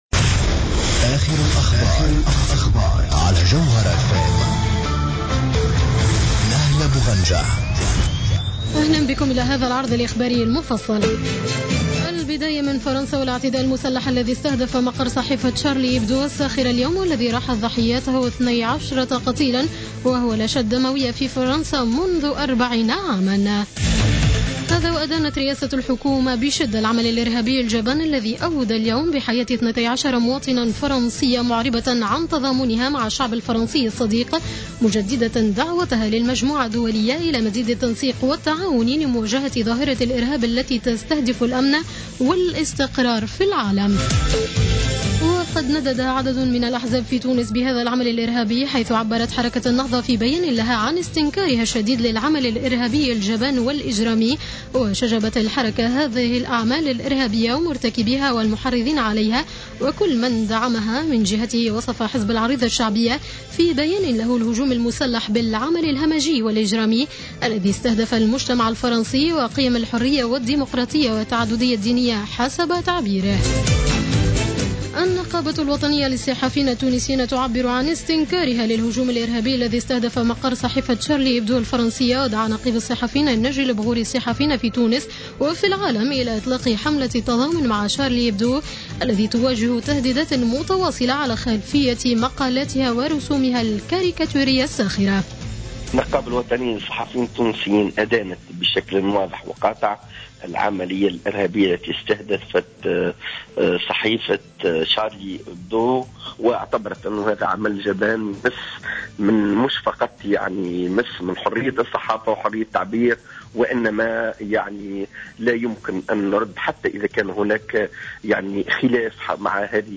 نشرة أخبار السابعة مساء ليوم الاربعاء 07-01-15